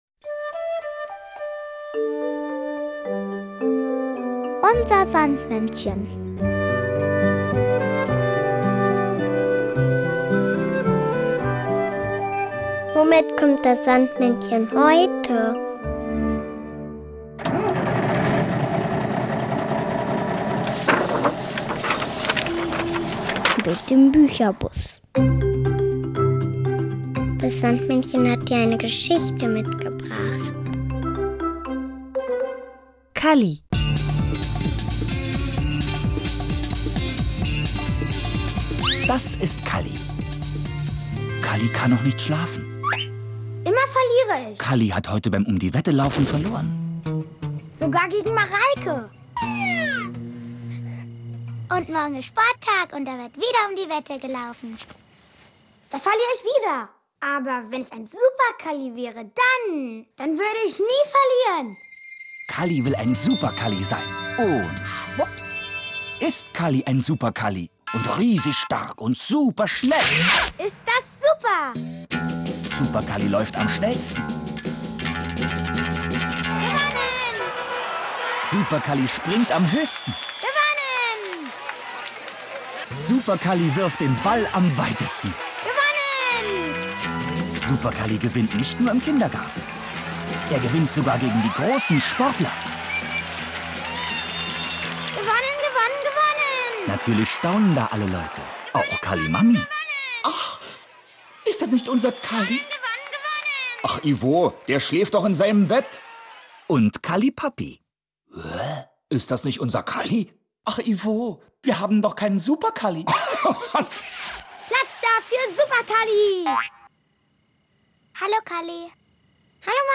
Das Sandmännchen hat dir aber nicht nur diese Geschichte mitgebracht, sondern auch noch das Kinderlied "Supermänsch" von Mine und Edgar Wasser.…